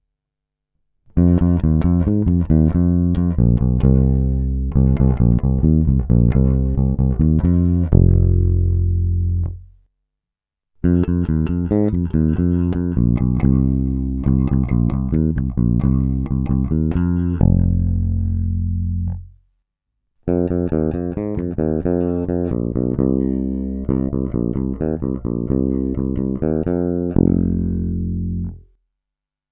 Následující nahrávky, není-li řečeno jinak, jsou provedeny rovnou do zvukové karty, jen normalizovány, jinak ponechány v původním stavu bez postprocesingu.